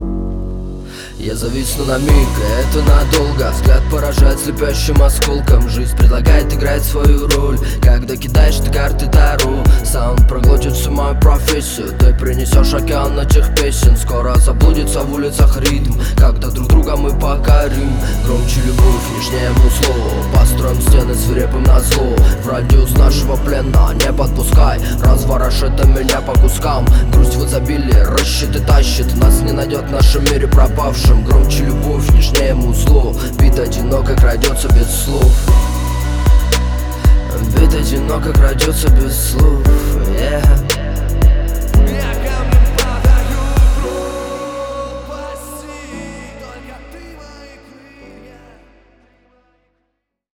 Вложения Rap.wav Rap.wav 8,5 MB · Просмотры: 288